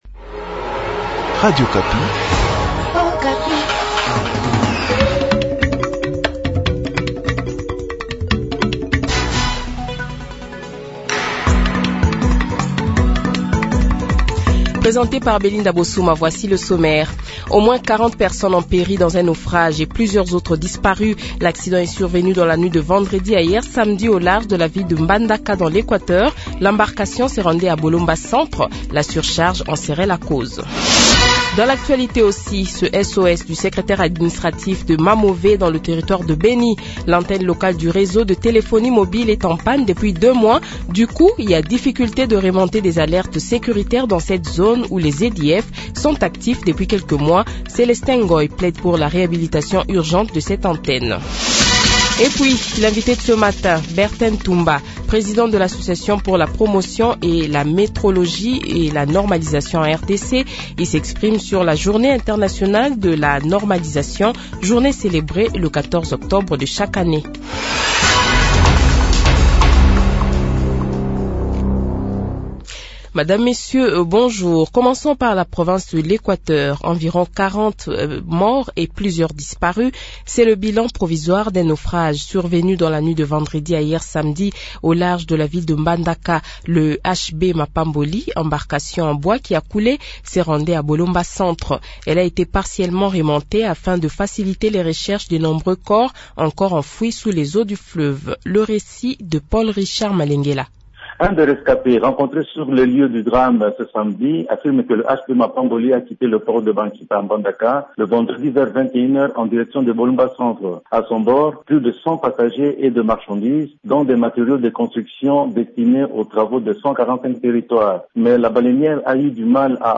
Le Journal de 7h, 15 Octobre 2023 :